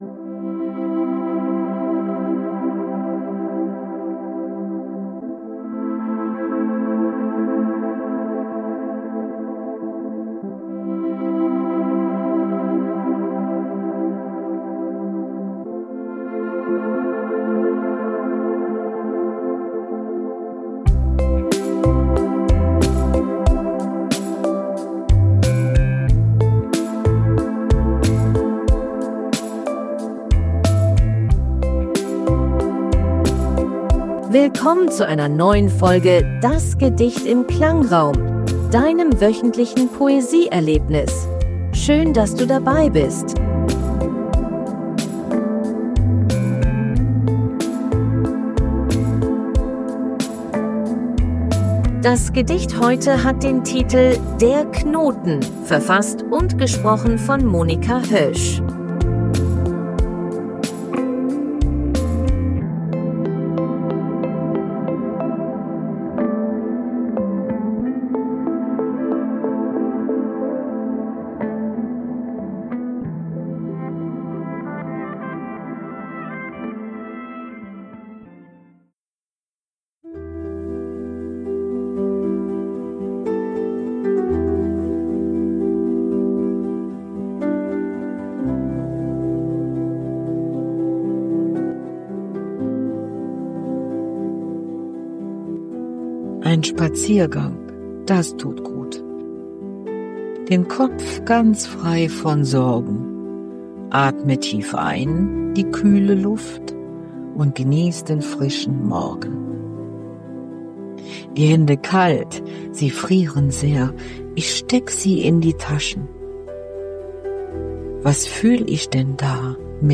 KI-generierter Musik.